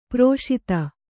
♪ proṣita